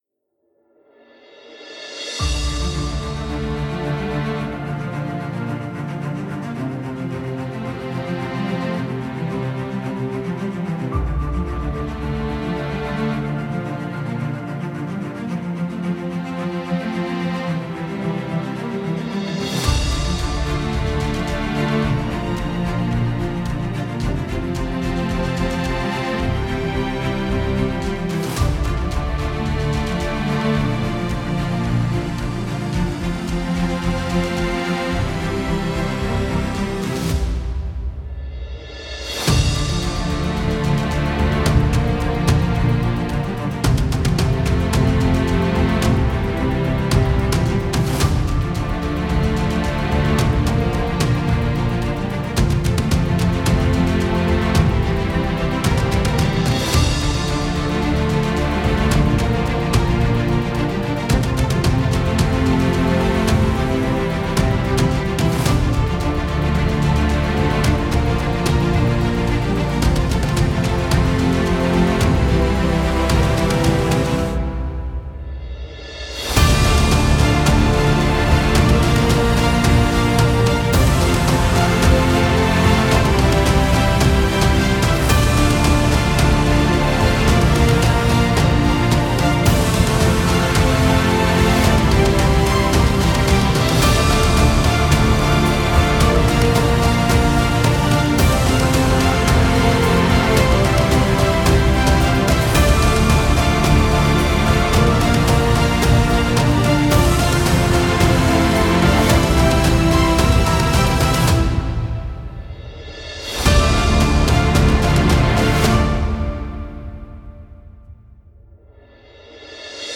Genre: filmscore, trailer.